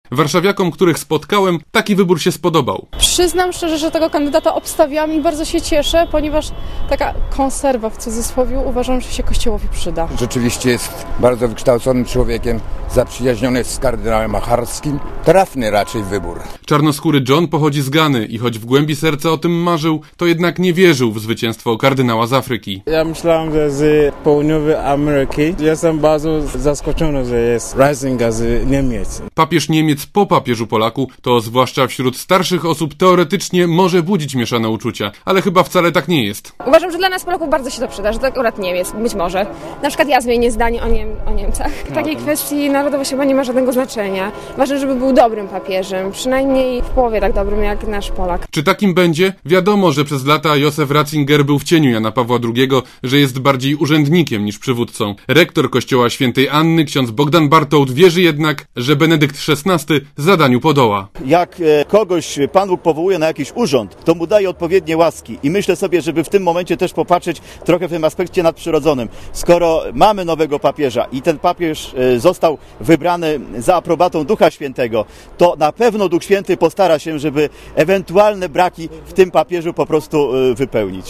* Posłuchaj, co o wyborze papieża sądzą mieszkańcy Warszawy*